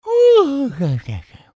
Play, download and share Paper Mario Yawn original sound button!!!!
paper-mario-yawn.mp3